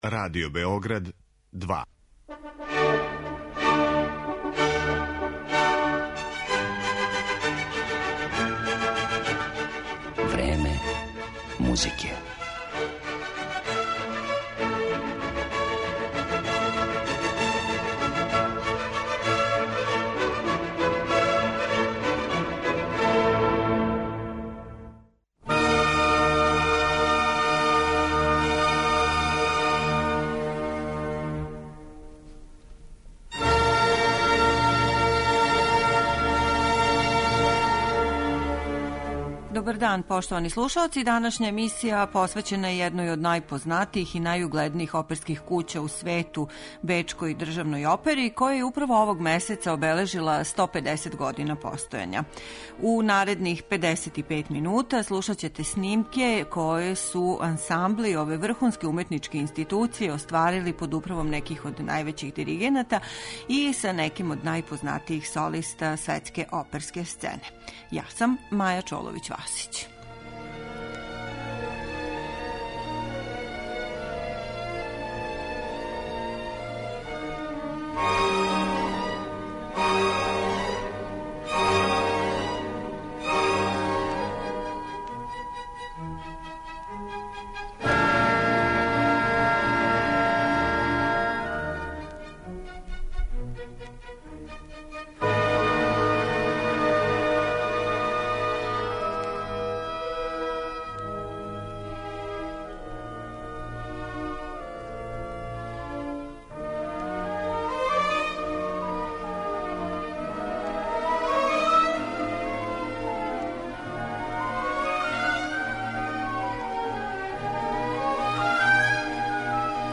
Данашња емисија посвећена је једној од најпознатијих и најугледнијих оперских кућа у свету - Бечкој државној опери, која је управо овог месеца обележила 150 година постојања. Слушаћете снимке које су ансамбли ове врхунске уметничке институције остварили под управом неких од највећих диригената и оперских солиста на светској музичкој сцени.